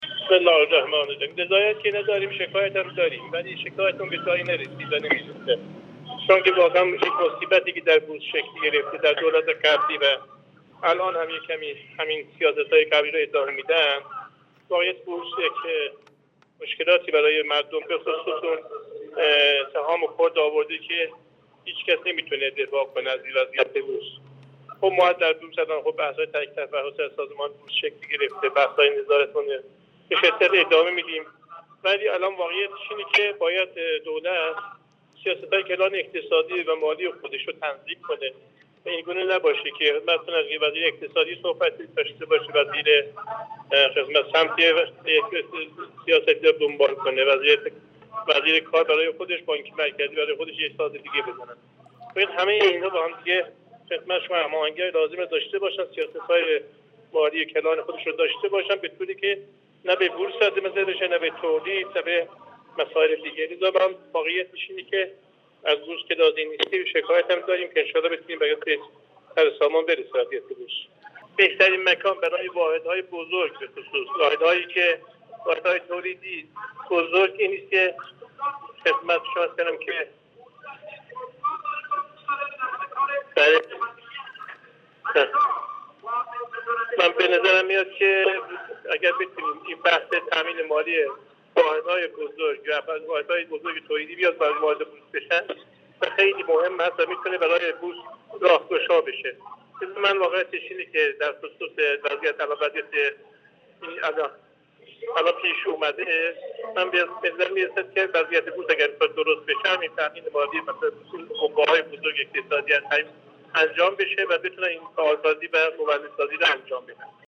محمد باقری بنابی، عضو کمیسیون اقتصادی مجلس شورای اسلامی در گفتگو با بورس نیوز اظهار کرد: اشکالات بوجودآمده بورس در دولت قبلی، موجب شکایت‌های متعددی از سوی مردم و سرمایه گذاران شد.